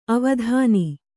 ♪ avadhāni